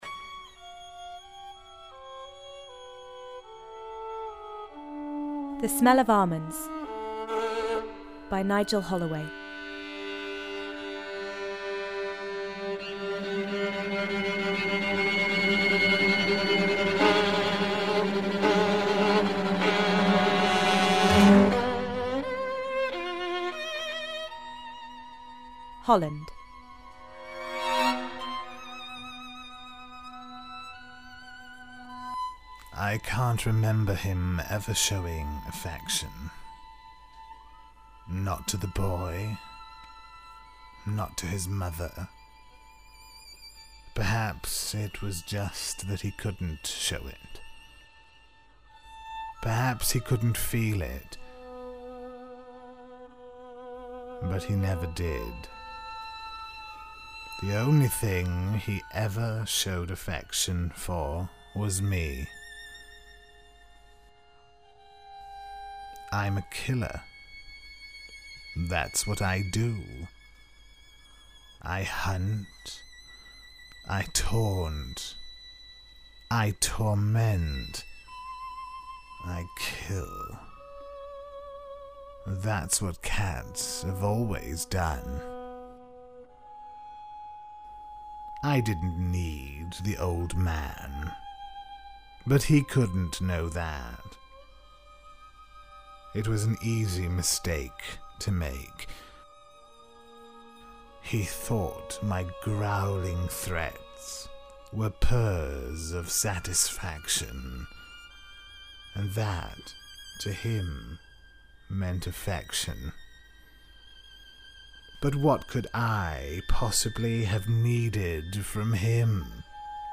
A play in one act charting the dark and unexpected secrets which hide behind the shattered and disjointed memories and imaginings of an old man and his cat.